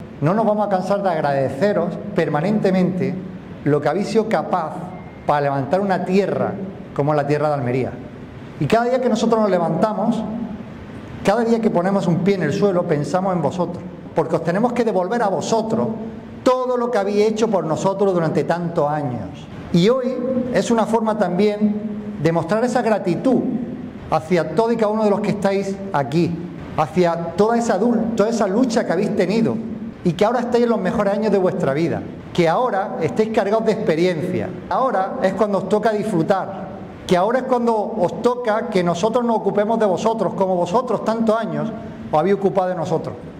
El presidente de la Diputación de Almería, Javier A. García; el presidente de la Diputación de Granada, Francisco Rodríguez; y el alcalde de Almuñécar, Juan José Ruiz, dan la bienvenida a los mayores de la provincia que vivirán esta experiencia estos días
06-04-mayores-almunecar-presidente.mp3